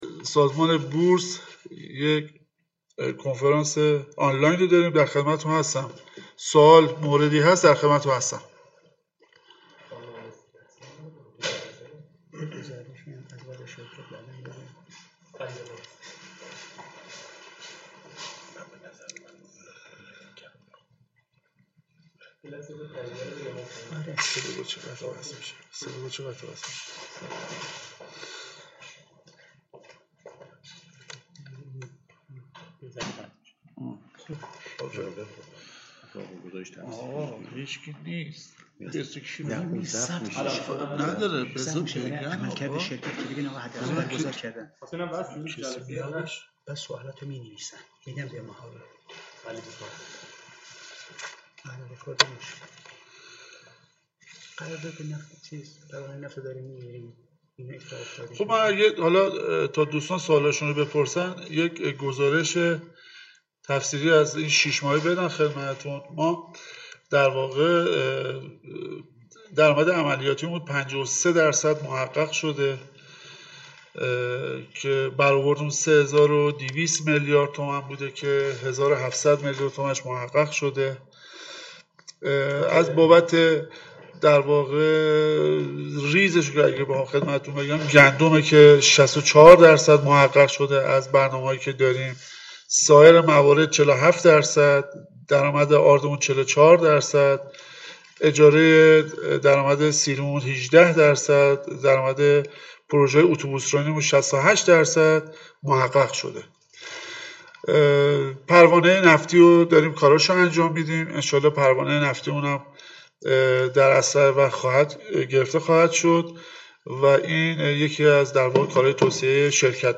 کنفرانس آنلاین پرسش و پاسخ سهامداران و مدیران شرکت حمل ونقل بین المللی خلیج فارس- نماد:حفارس